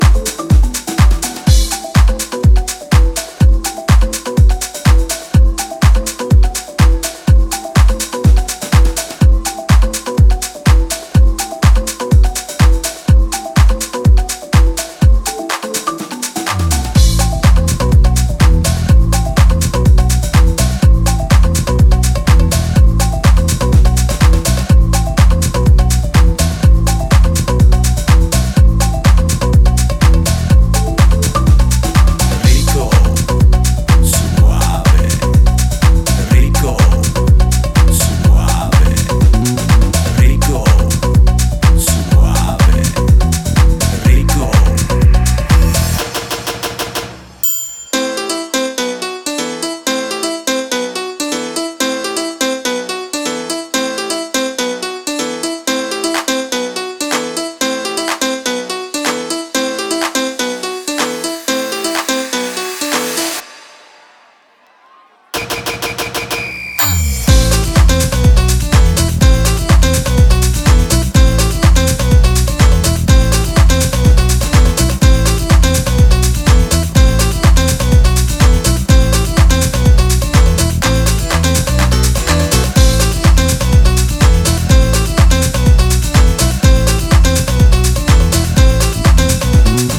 軽快なラテン・タッチのメロディーやパーカッションが躍動する